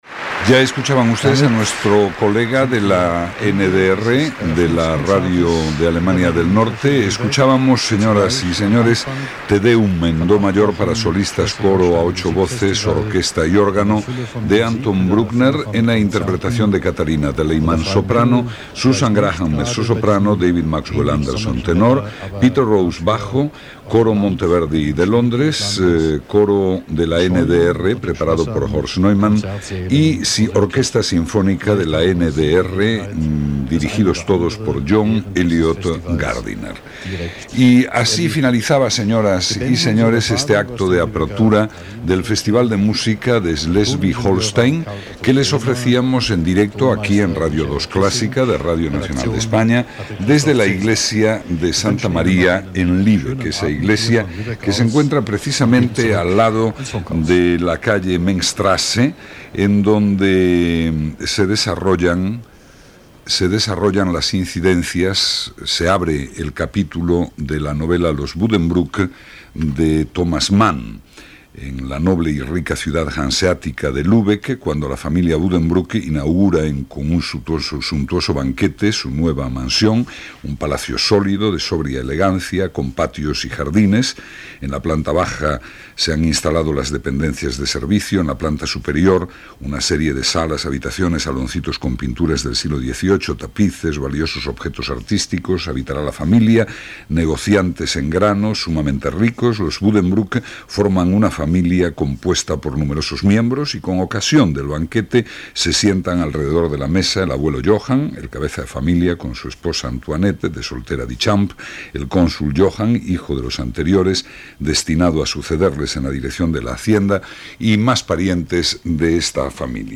Final de la transmissió del Schleswig-Holstein Music Festival des de Lübeck (Alemanya): obra escoltada, intèrprets i dades de la ciutat de Lübeck. Connexió amb el 42 Festival Internacional de Música y Danza de Granada: ambient i estat del temps
Musical
FM